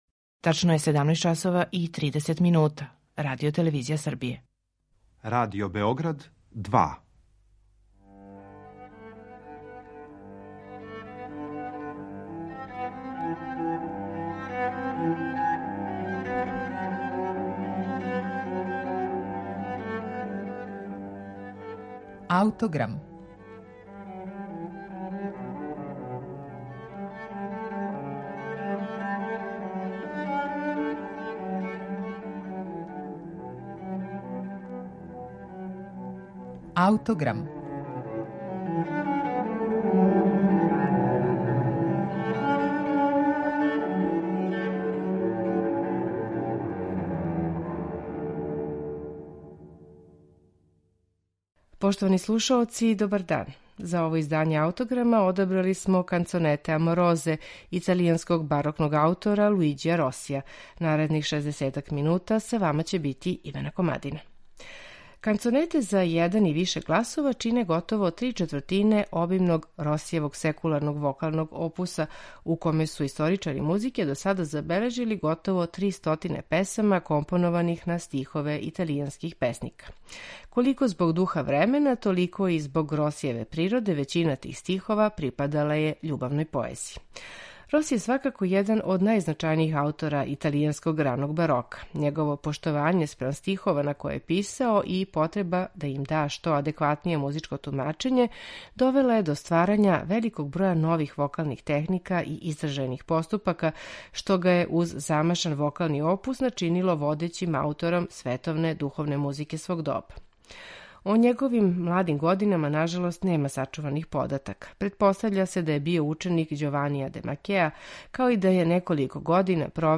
Канцонете за један и више гласова чине готово три четвртине обимног вокалног опуса Луиђија Росија (1597-1653), једног од највећих мајстора италијанског раног барока.
чембало и оргуље
виола да гамба.